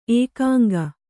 ♪ ēkāŋga